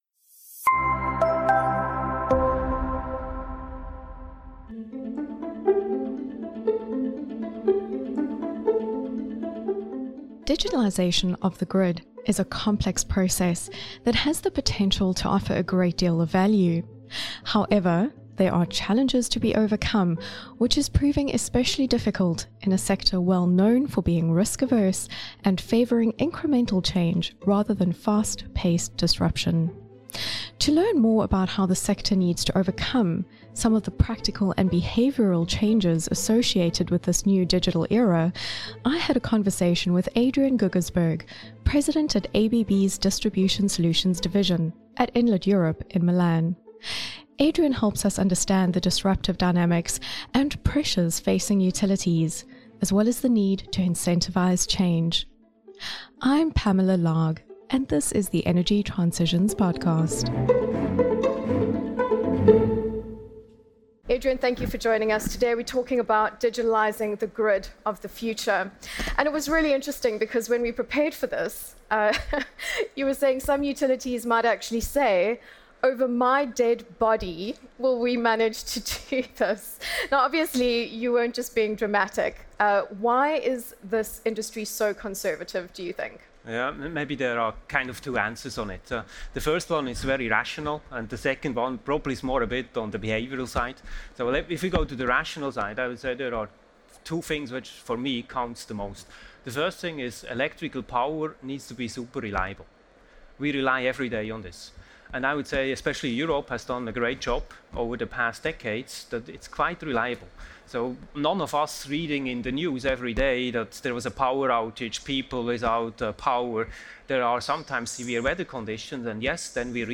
at Enlit Europe